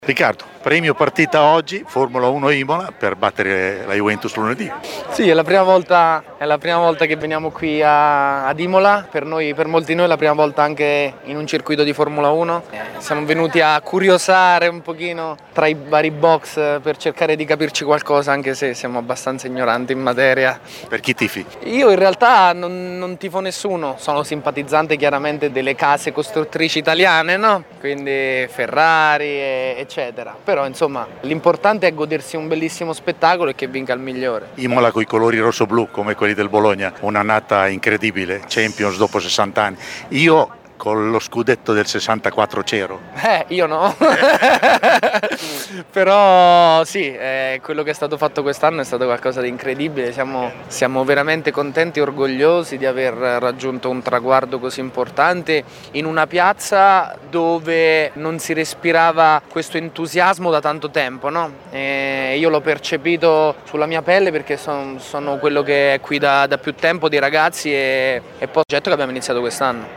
La carica dei 200mila a Imola: nuova festa per Verstappen e LeClerc porta la Ferrari a podio | Radio Bruno